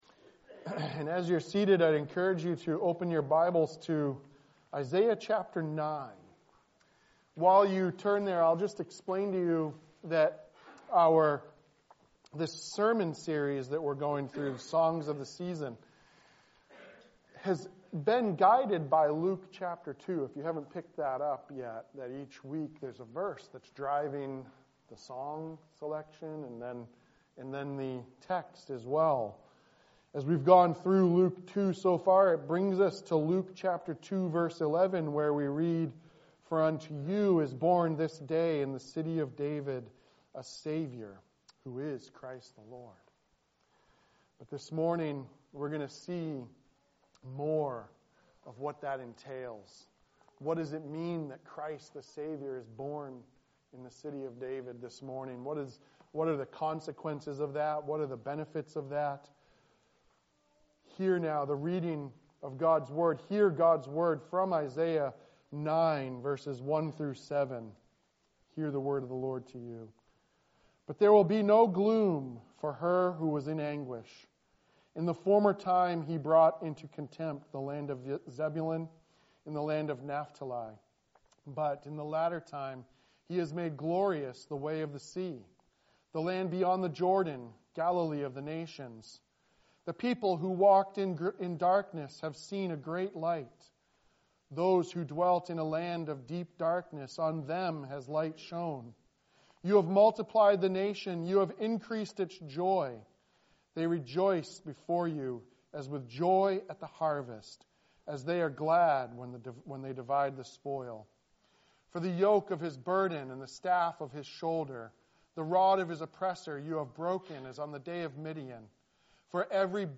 December 15, 2024 Songs of the Season series Advent 2024 Save/Download this sermon Luke 2:11 Other sermons from Luke 11 For unto you is born this day in the city of David a Savior, who is Christ the Lord.